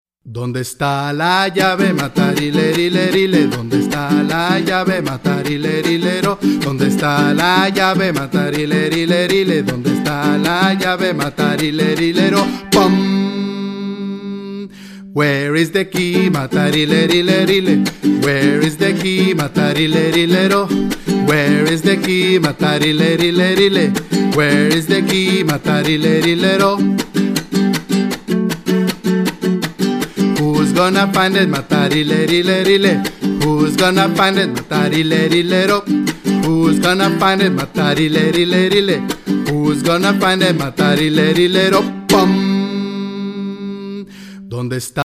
. it’s all there in its multi-instrumental glory.